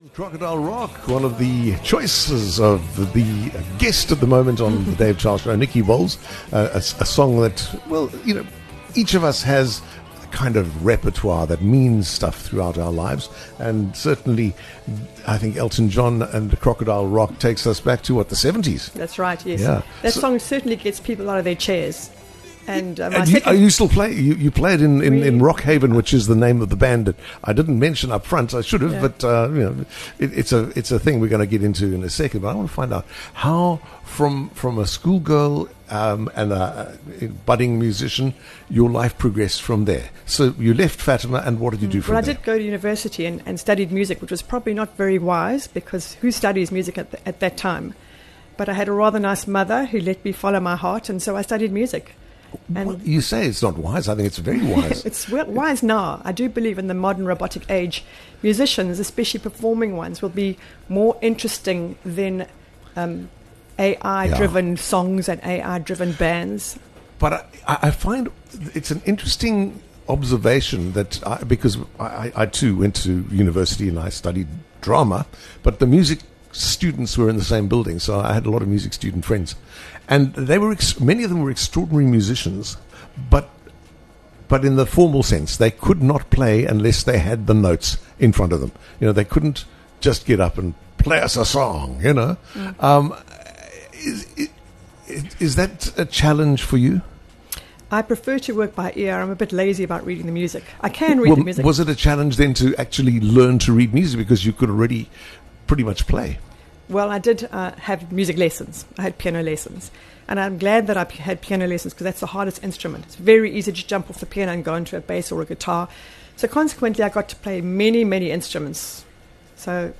Whether it’s your favourite songs, meaningful milestones, or the moments that shaped you, come and share them live on air.